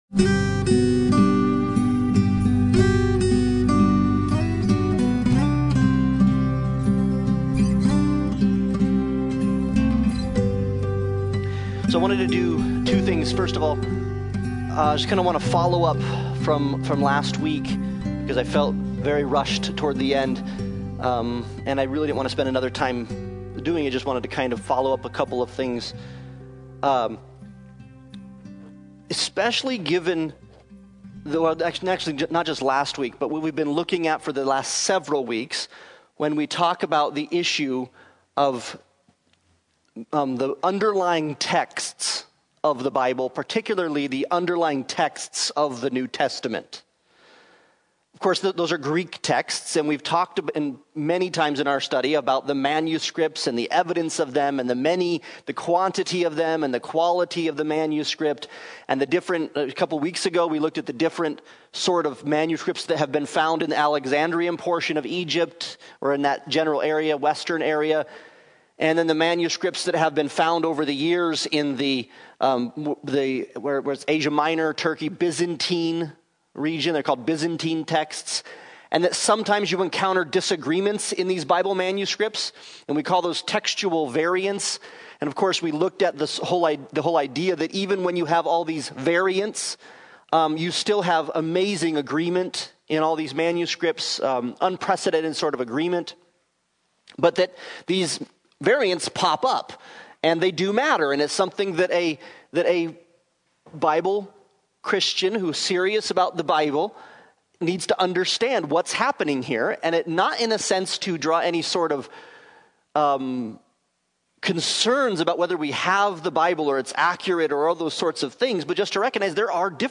God's Word Service Type: Sunday Bible Study « The Gospel Works